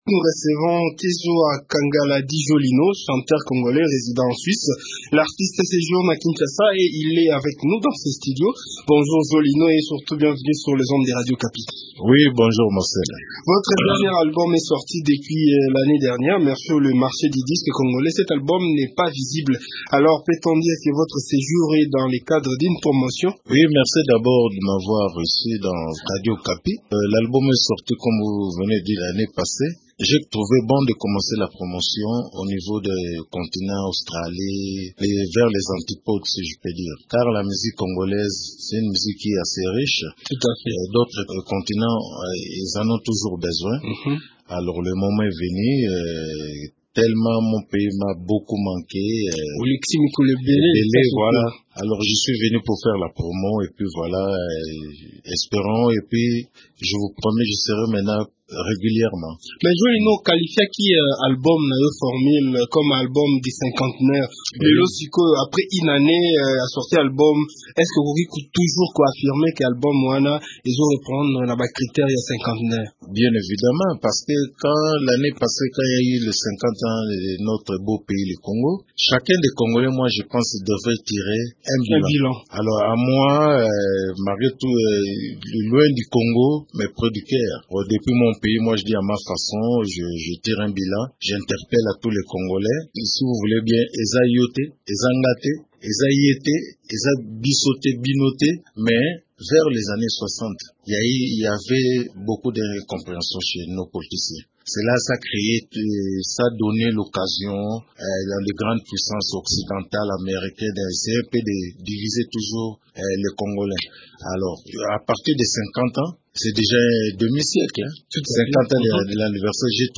Dans un entretien